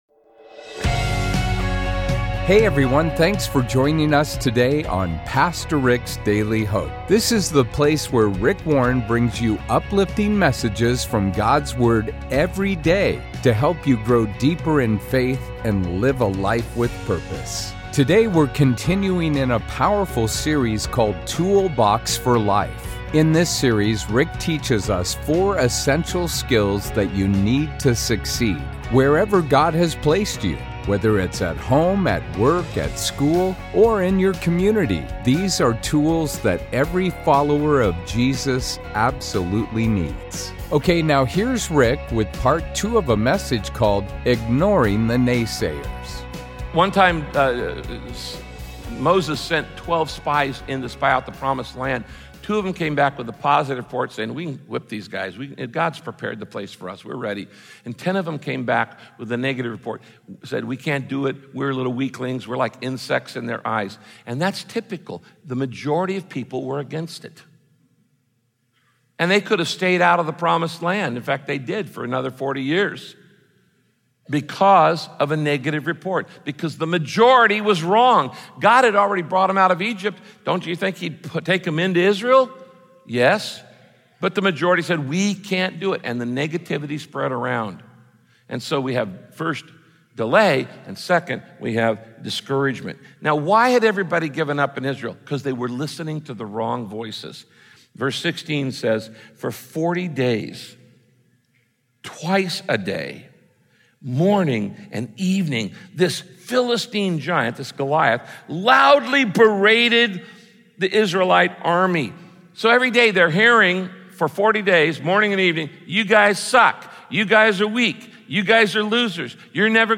In this sermon, Pa…